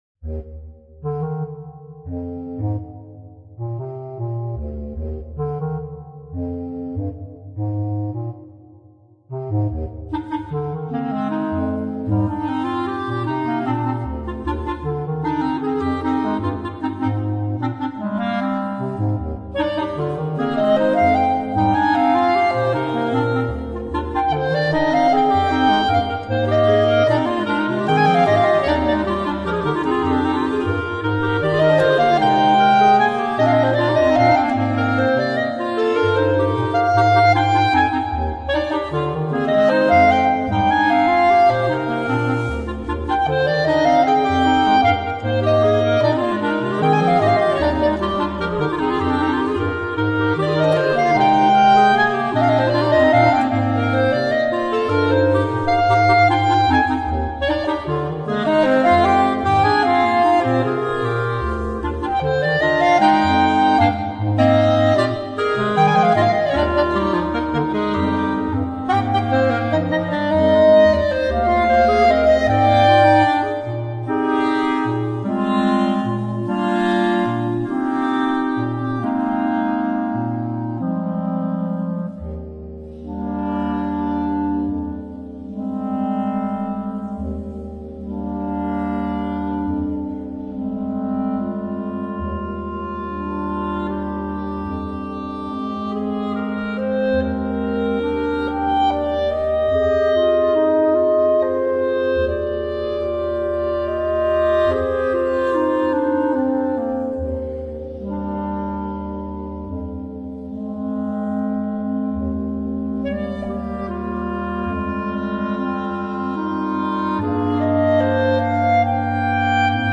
Per quartetto di clarinetti